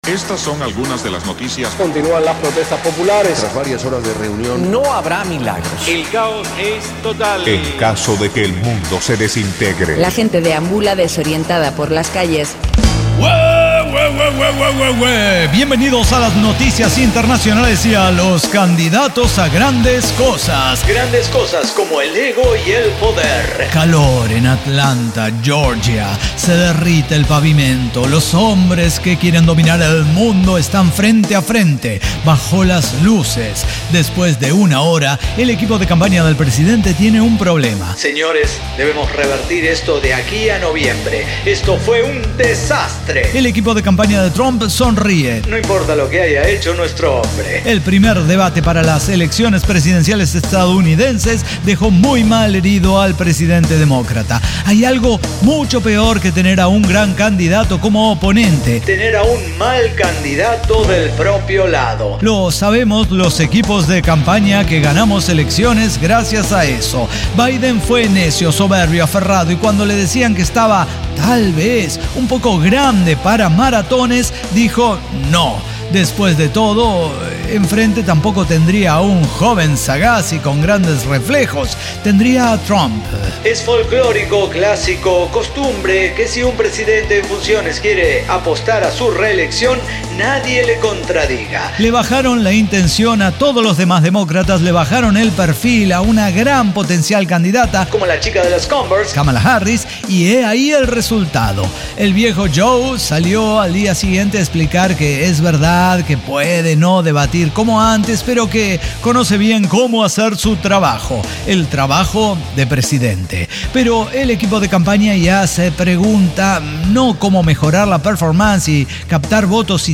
ECDQEMSD podcast El Cyber Talk Show – episodio 5816 El Mal Candidato